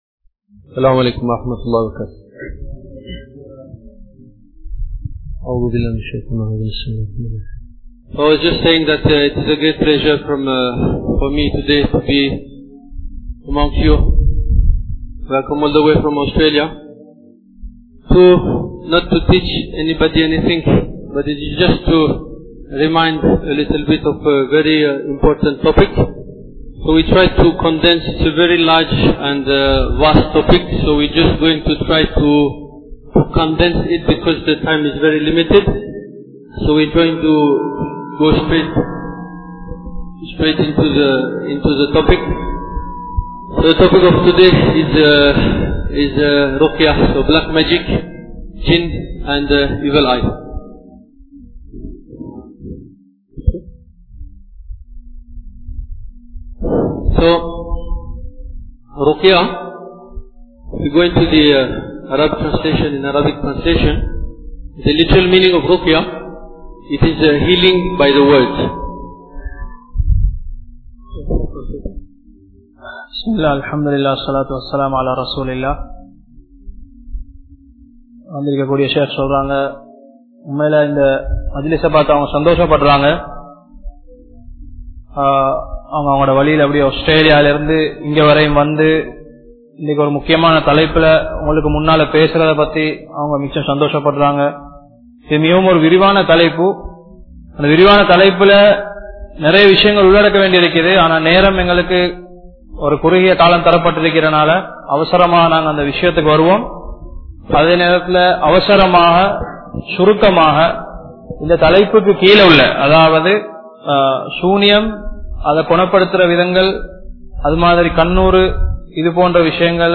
Ungalukku Sooniyam Seiya Pattullatha Enpathai Evvaaru Arivathu? (உங்களுக்கு சூனியம் செய்யப்பட்டுள்ளதா என்பதை எவ்வாறு அறிவது?) | Audio Bayans | All Ceylon Muslim Youth Community | Addalaichenai
Dehiwela, Muhideen (Markaz) Jumua Masjith